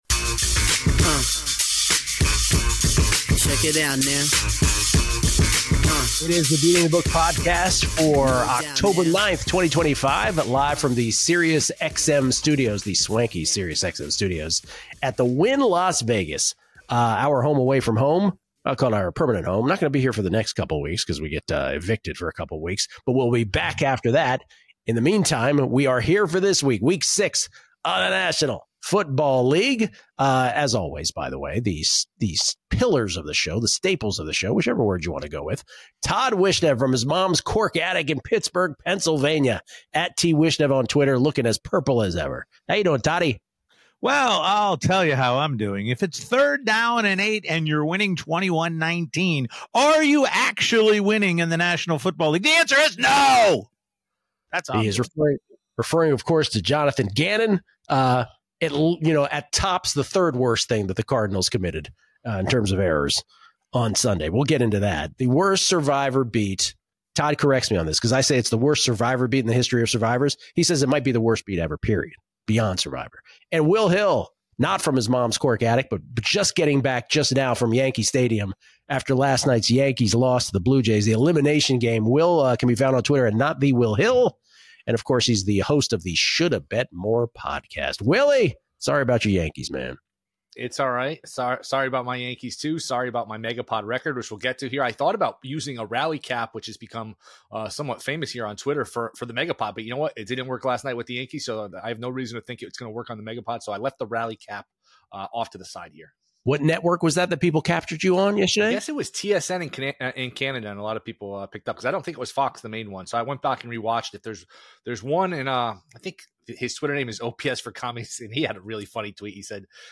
The quartet gives you their best bets of the week, best teaser options, which big favorite is most likely to lose outright, and the game they want no part of.